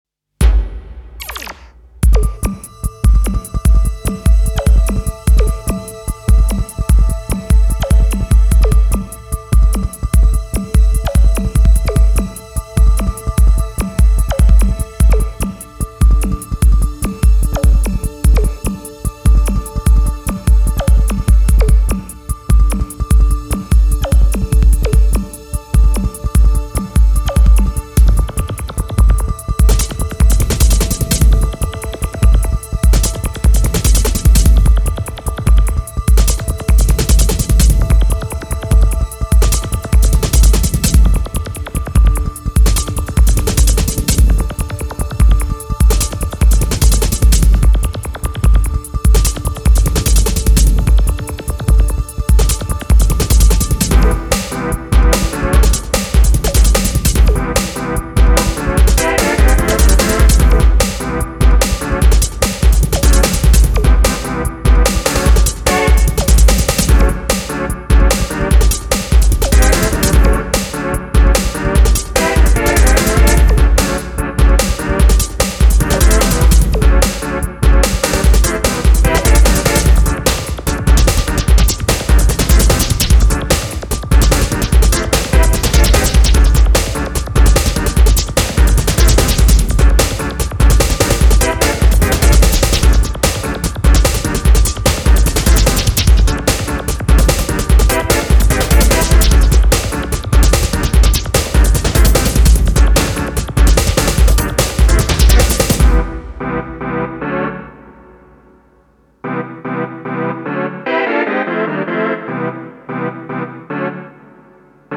さらにテンポを上げたブレイクビーツ仕立て